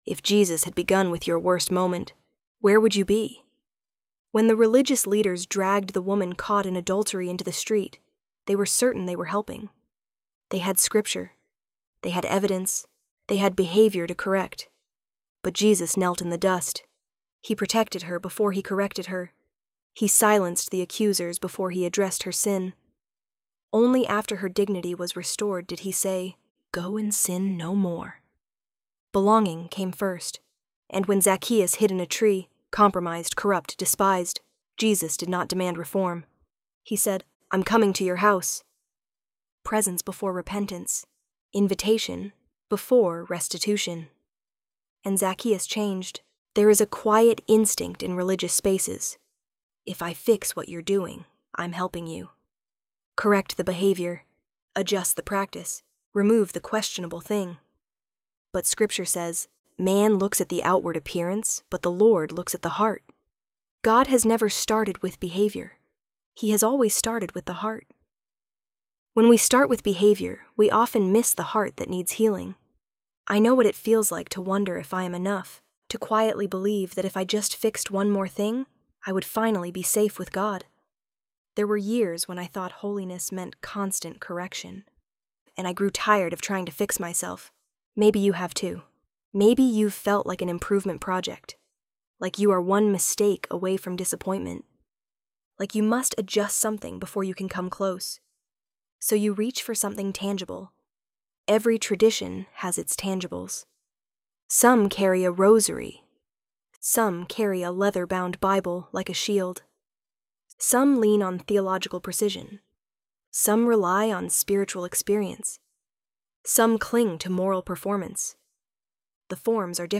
ElevenLabs_Treasured_Possession.mp3